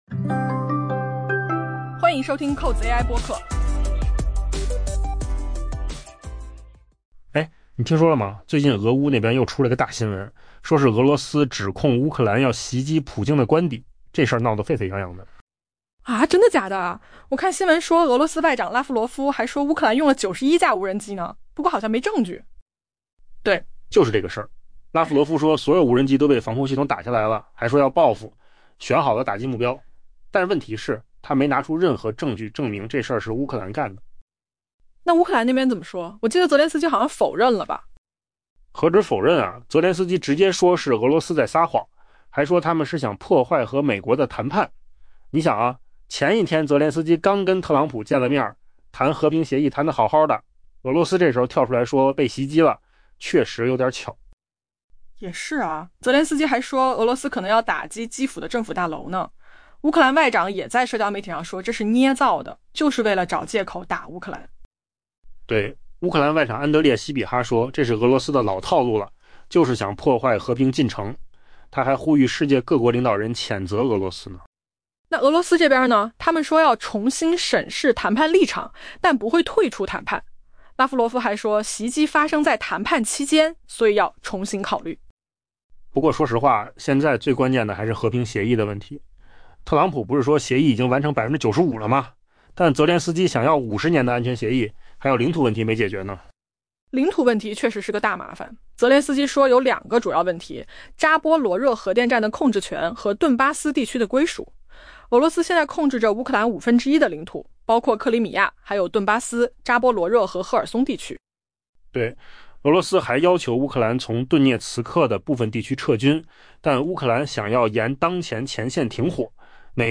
AI 播客：换个方式听新闻 下载 mp3 音频由扣子空间生成 俄罗斯周一指控乌克兰试图袭击总统普京位于俄北部的官邸，但并未提供证据支持这一说法。